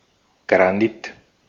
Ääntäminen